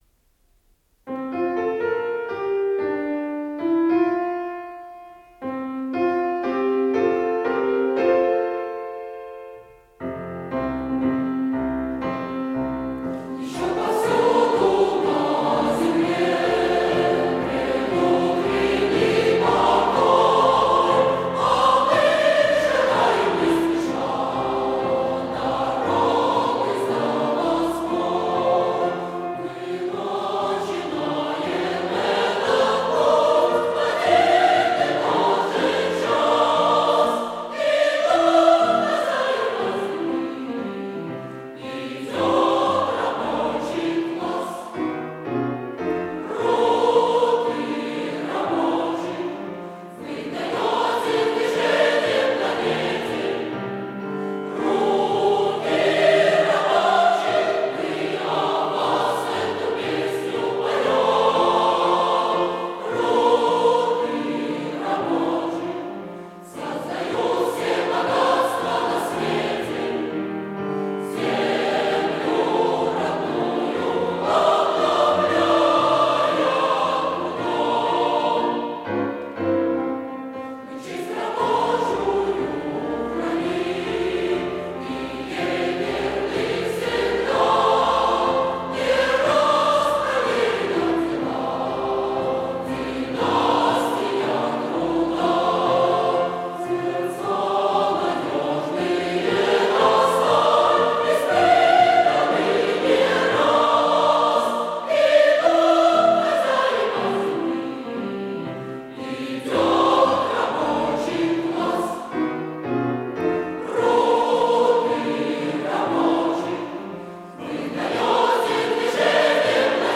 Стерео дубль.